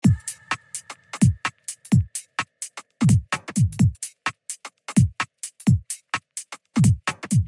OLIVER_128_drums_tight_london_garage_full